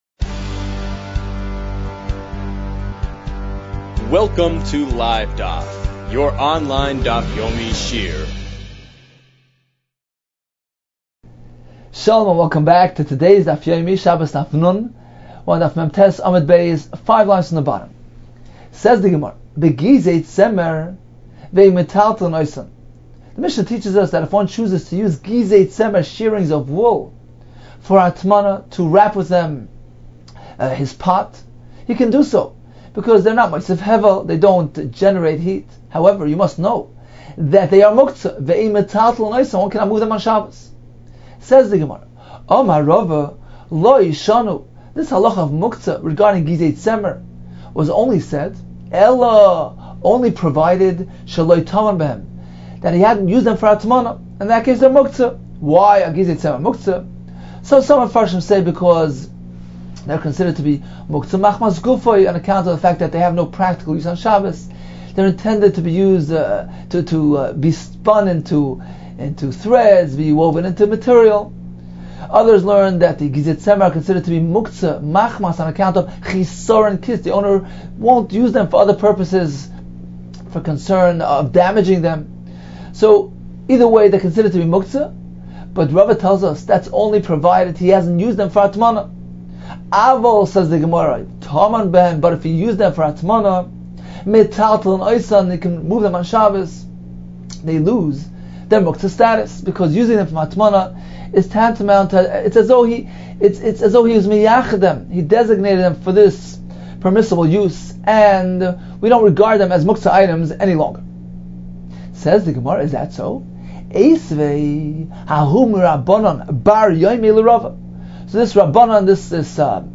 The following Daf Yomi Shiur on Shabbos Daf 50 is part of our daily Daf Yomi program featuring a daily Daf Yomi audio and video Shiur and daily Daf Yomi review diagrams, which can be downloaded free.